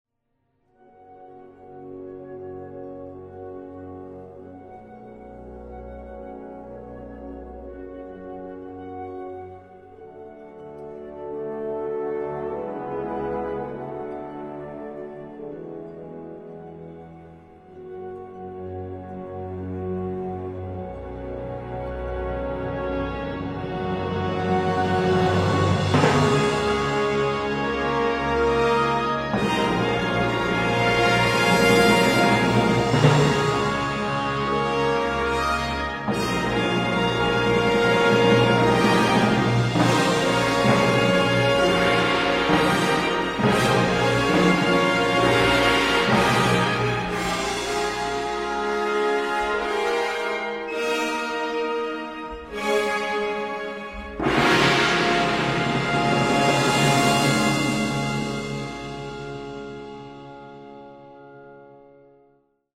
tres bocetos sinfónicos